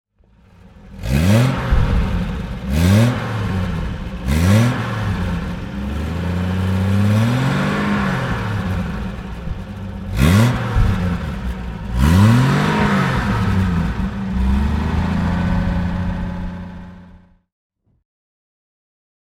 Austin-Healey 3000 Mk II BJ7 (1962) - Starten und Leerlauf